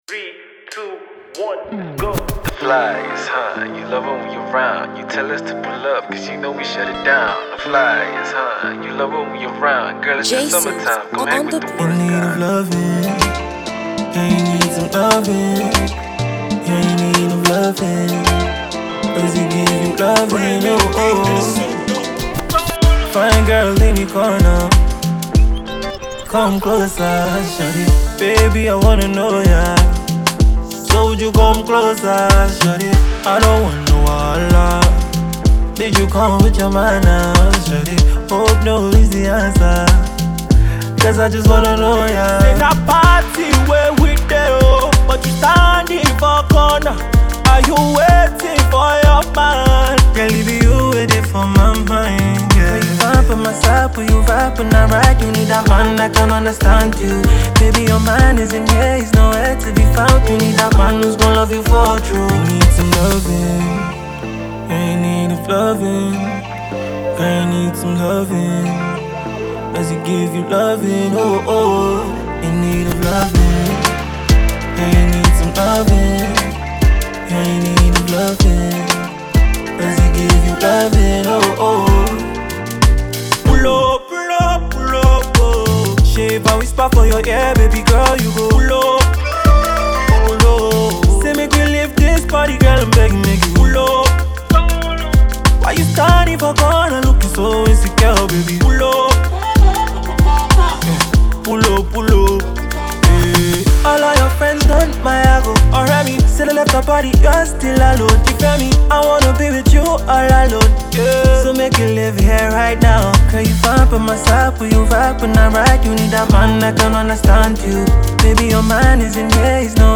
authentic sounds and refreshing vocals
an R&B soothing track with a fusion of Afro Pop.